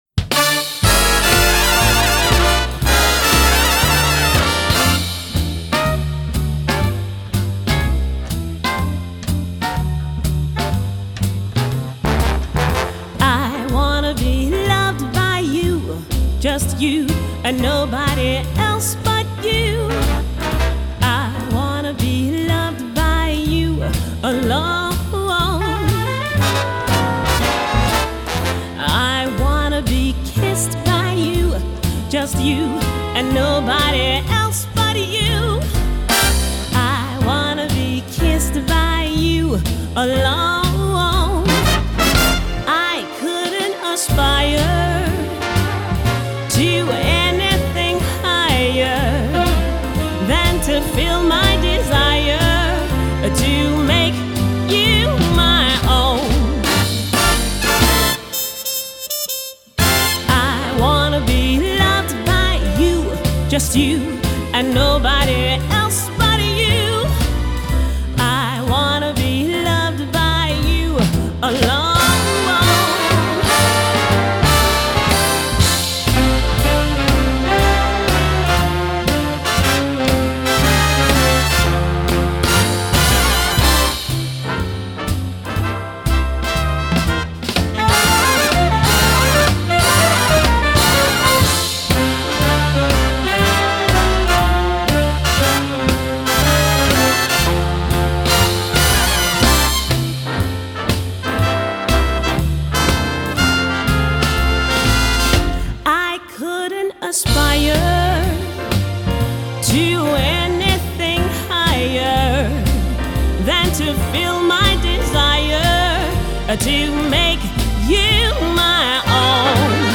The ultimate Swiss big band sound.